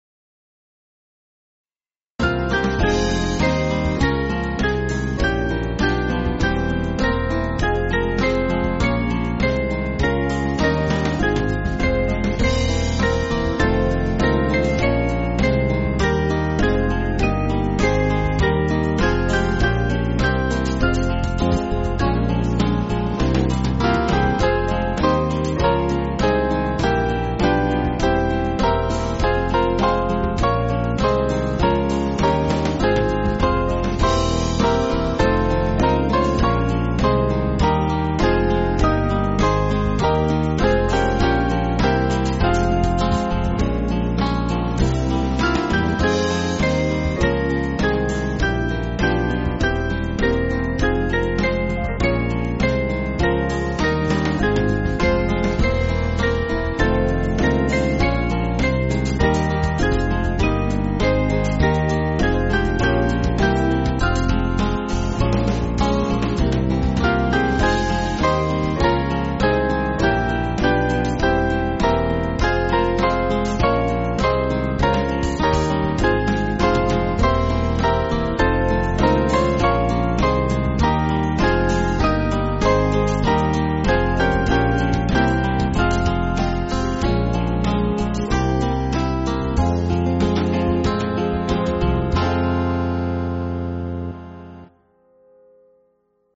Small Band
(CM)   4/Fm